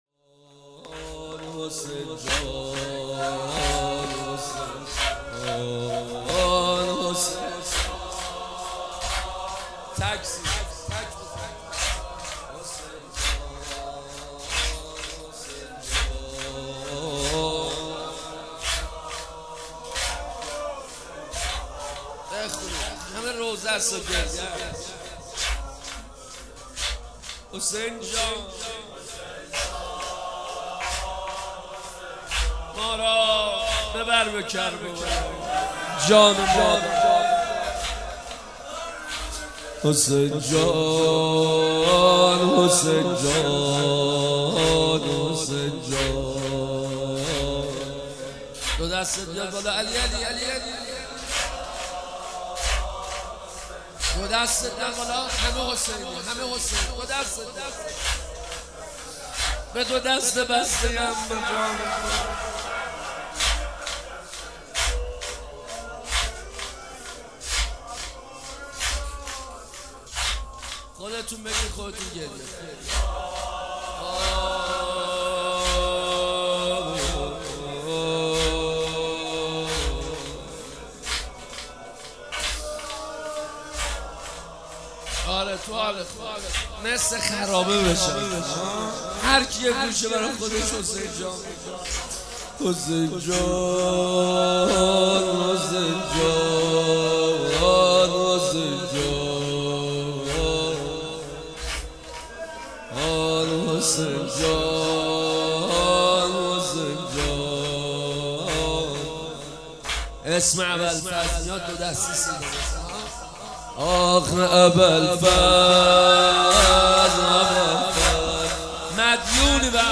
روضه هفتگی/هیت منادیان کربلا